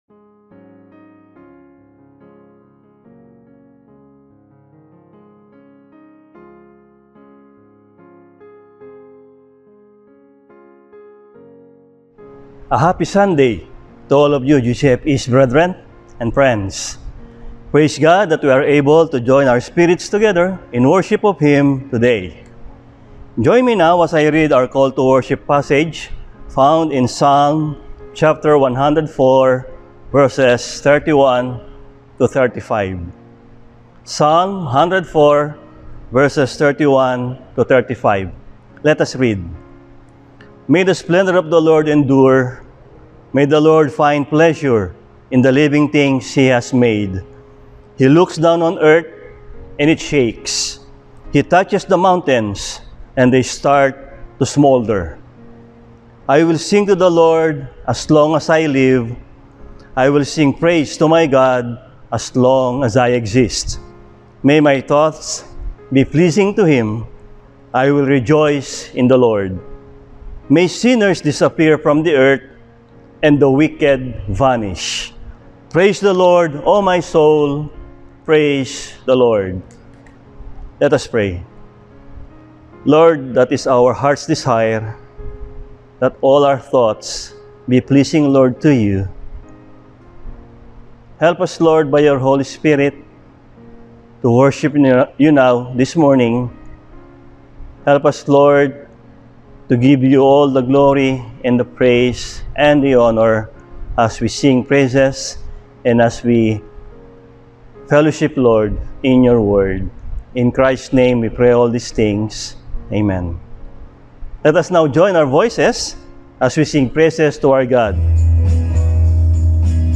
Service: Sunday